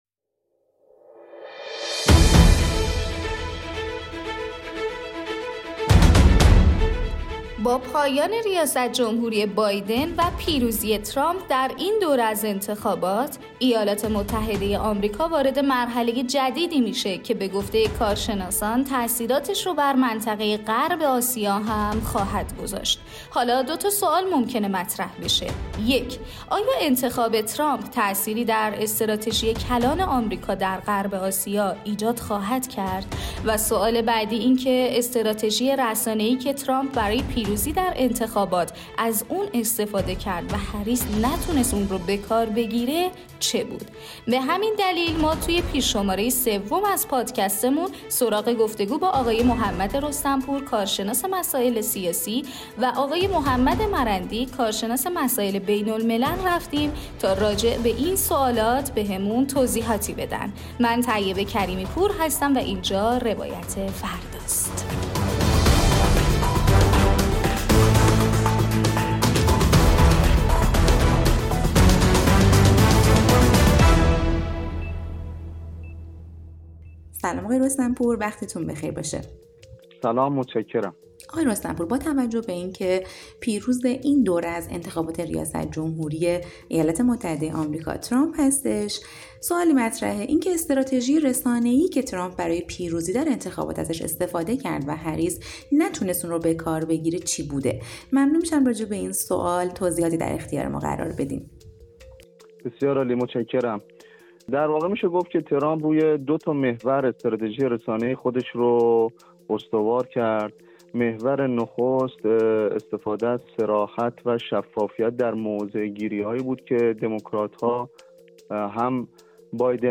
گفتگو «روایت فردا» با کارشناسان سیاسی و بین‌الملل